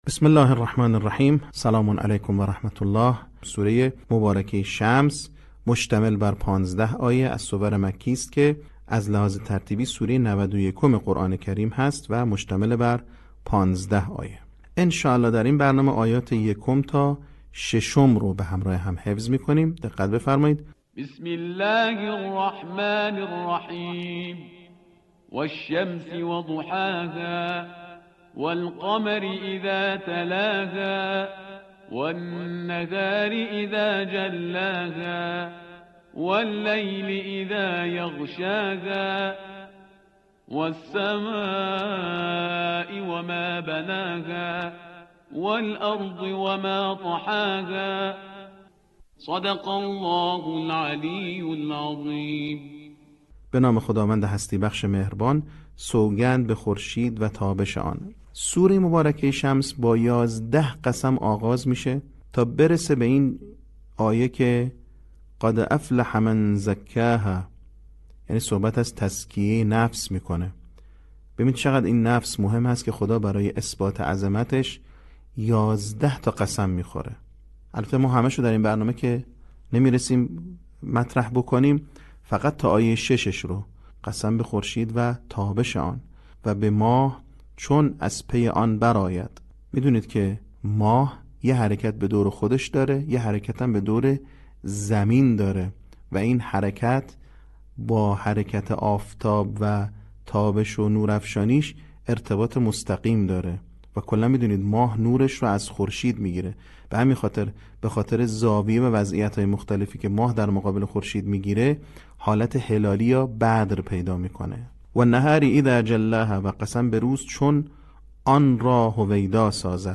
صوت | آموزش حفظ سوره شمس